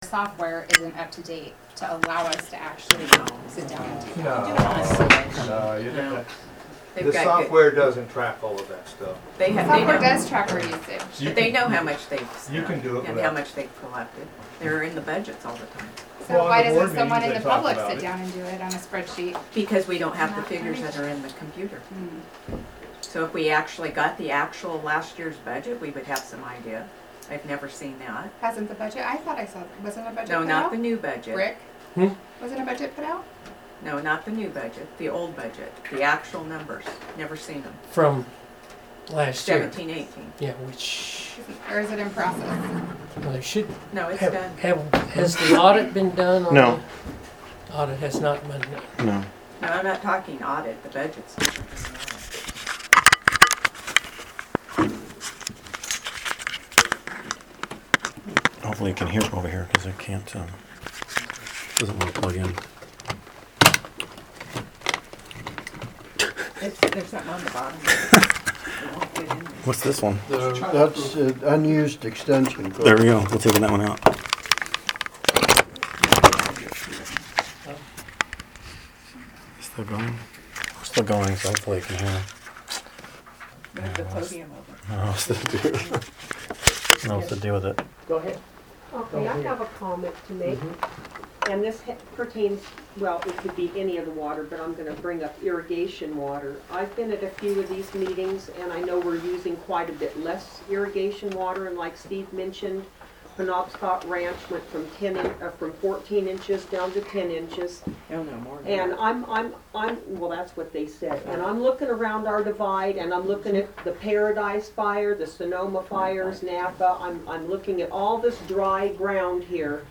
Special Meeting